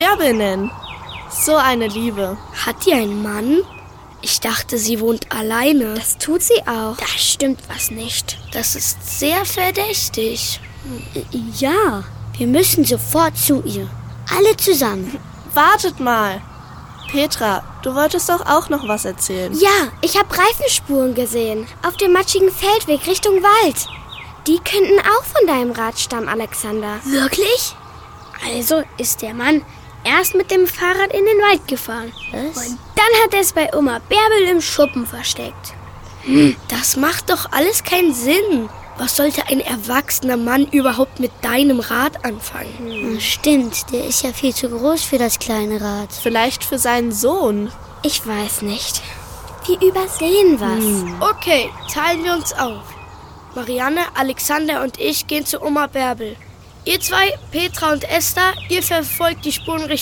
Genres : Hörspiel, Kids
Hoerbeispiel_Kids-Folge1.mp3